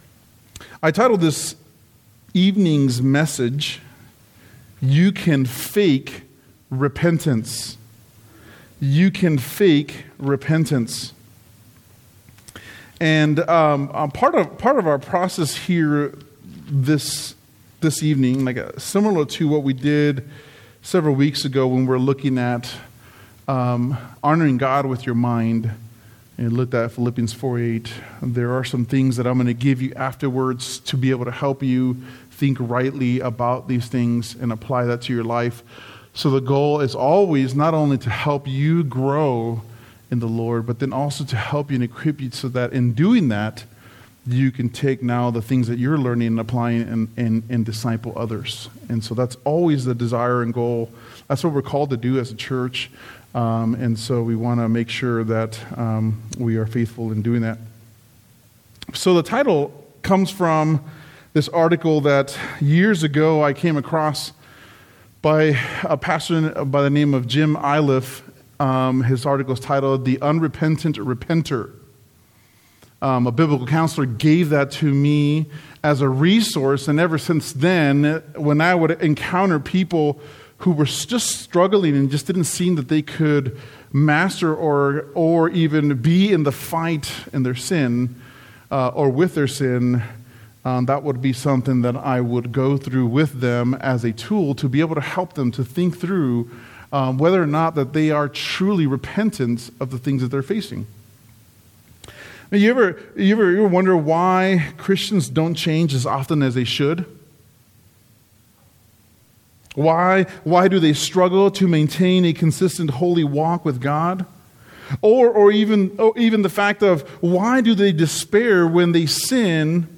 The message on Wednesday